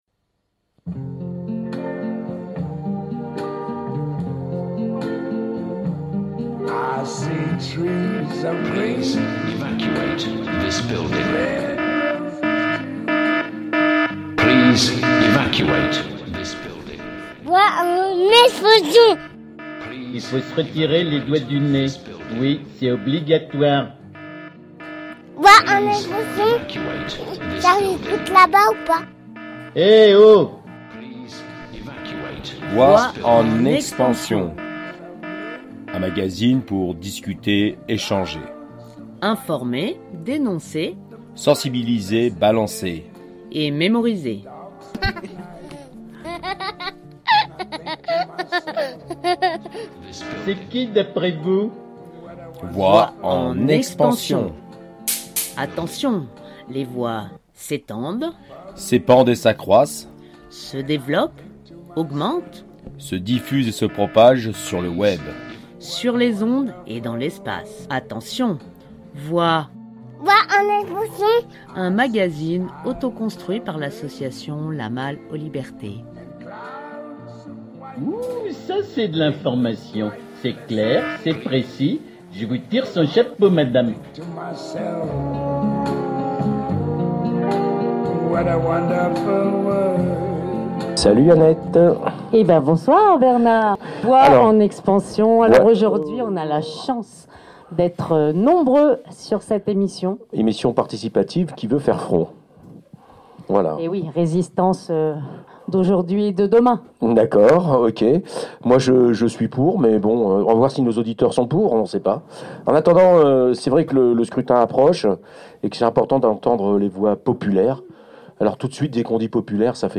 Télécharger en MP3 Et si demain on éteignait toutes les voix dissidentes, Et si la liberté d’expression n’était plus, Et si demain c’était aujourd’hui… Alors on laisse notre micro ouvert et ils nous ont dit leurs inquiétudes, leurs colères, leurs espoirs… Inutile de dire que dans cette grange du Vercors les mots ont raisonnés amèrement…